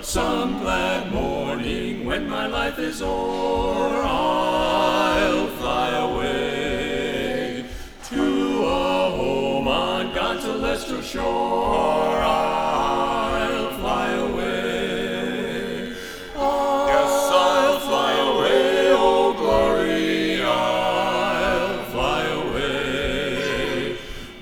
A Barbershop Quartet